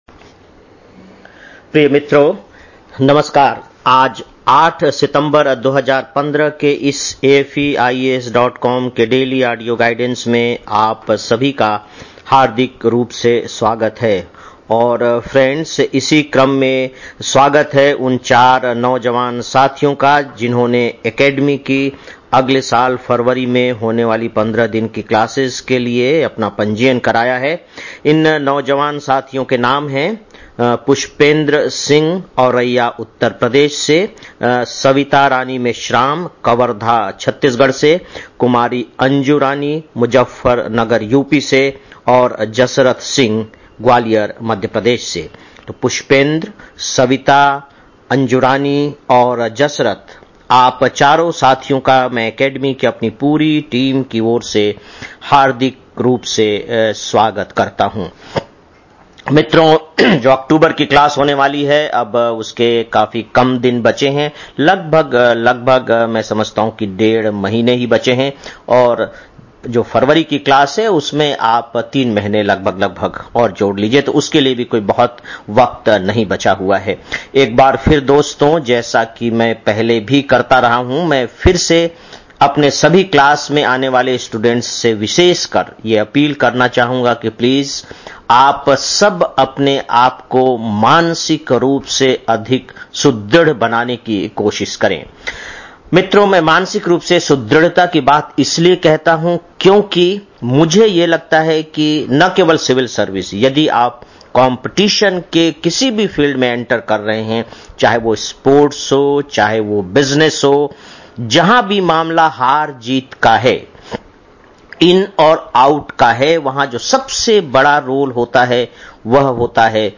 08-09-15 (Daily Audio Lecture) - AFEIAS